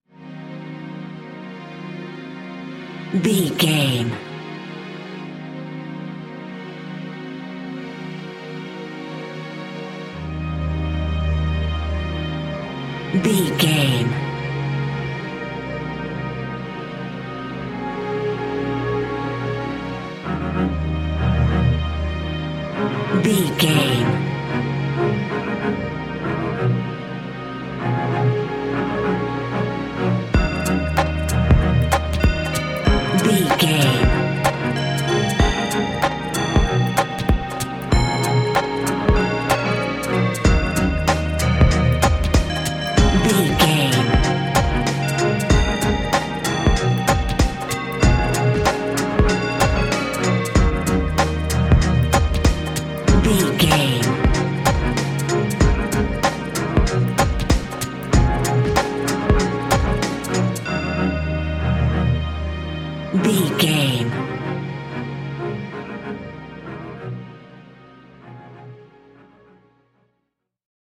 Hip Hop Cool Music.
Aeolian/Minor
E♭
electronic
synth drums
synth leads
synth bass